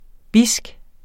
Udtale [ ˈbisg ]